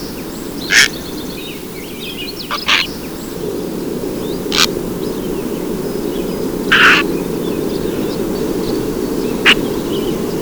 Great Blue Heron
Ardea herodias
VOZ: Un graznido grave al ser molestado, pero usualmente mudo fuera de las colonias de cría.